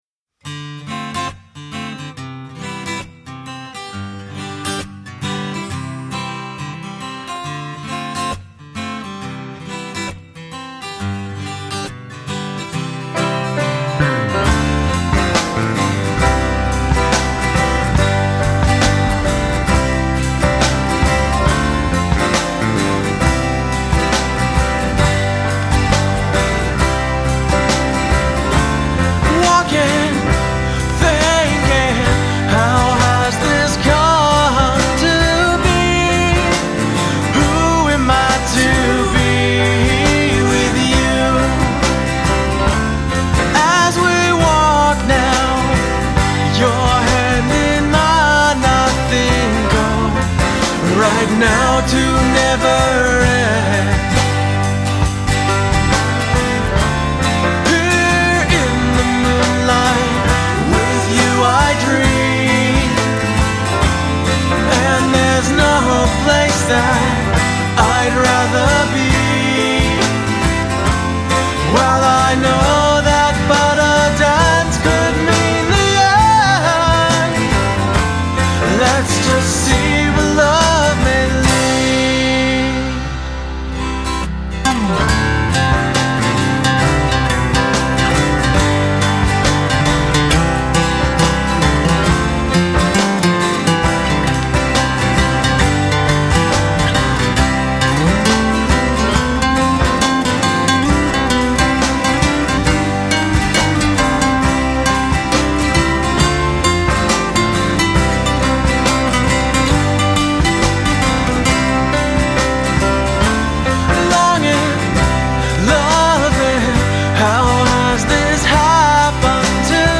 Guitar, Bass Guitar, Vocals
Drums
Piano